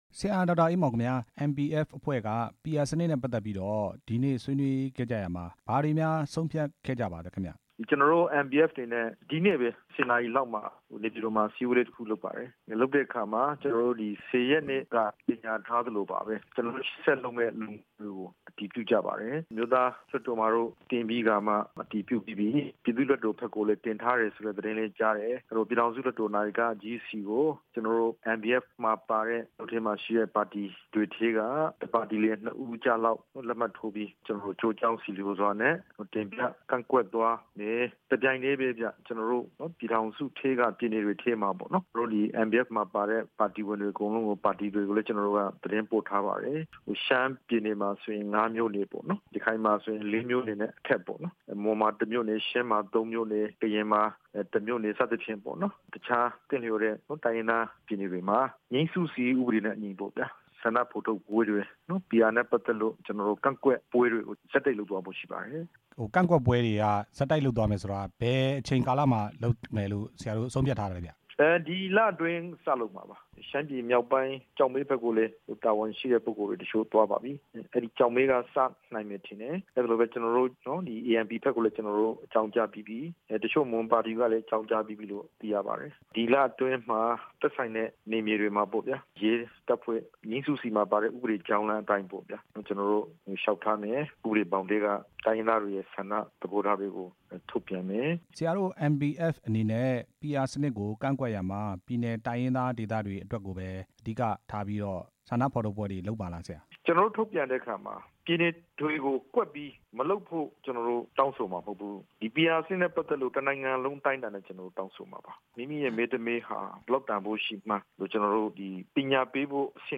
ရခိုင်အမျိုးသားပါတီက ဒေါက်တာအေးမောင်နဲ့ မေးမြန်းချက်